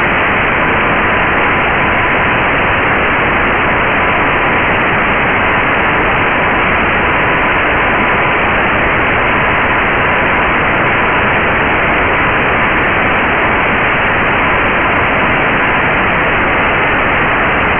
So we simulate a single morse signal with just noise and a dot time of 0.2 seconds or 6 words per minute and we adjust the settings of the QRSS decoder to that speed.
Audio file 10 dB higher (10x power) than the QRSS reference signal, 6 words per minute